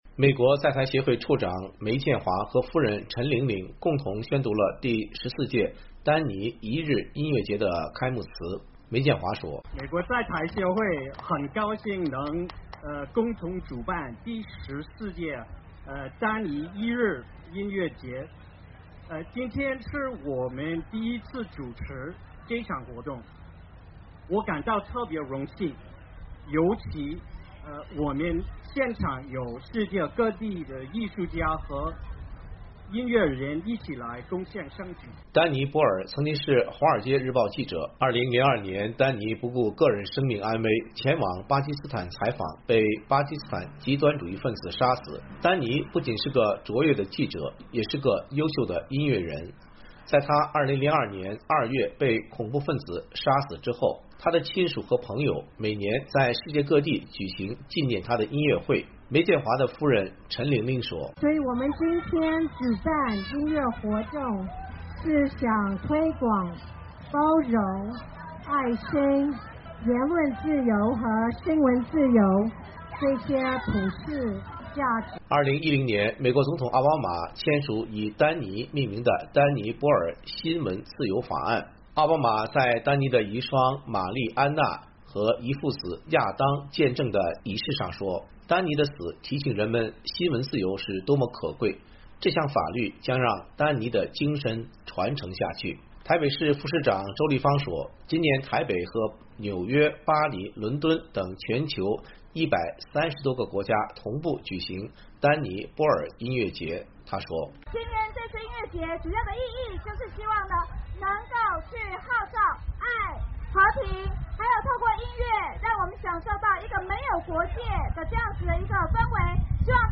十多个台湾及海外的乐团带来各种不同的音乐，包括摇滚，蓝调，舞曲，非洲打击乐，拉丁美洲音乐，客家民谣等。